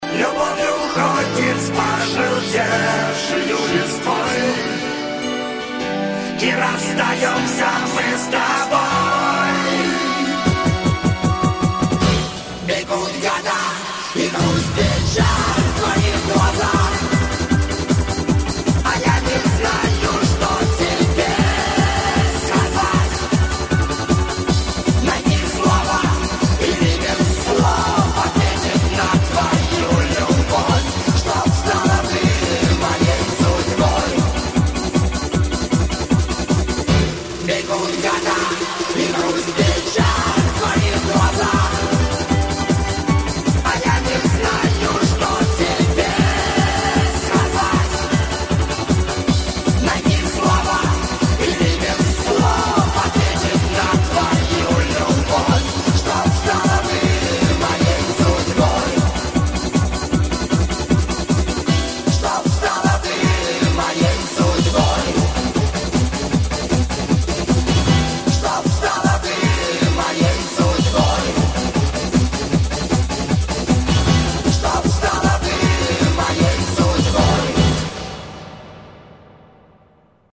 • Качество: 128, Stereo
Интересно поёт. Почти шансон